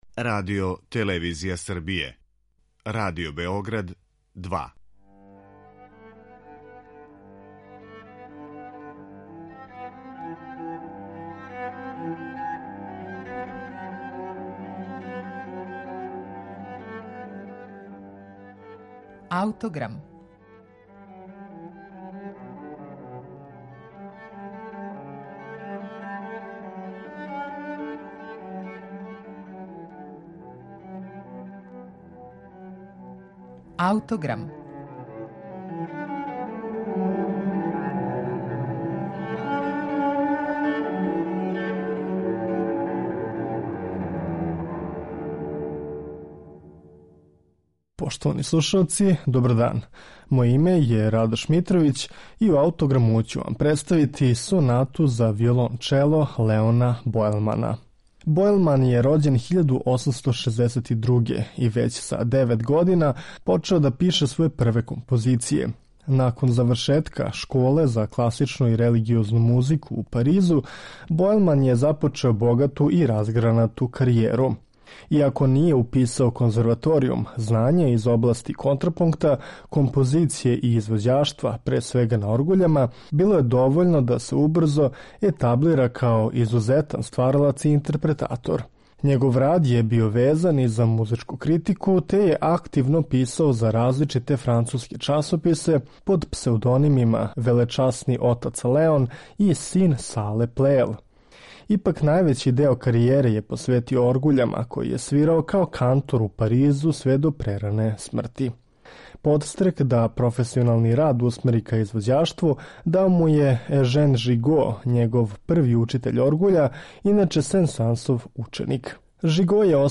Ми ћемо слушати тако његову сонату за виолончело, која умногоме одржава поетику раноромантичарскиг израза.